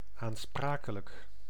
Ääntäminen
IPA : /ˈlaɪ̯əbəl/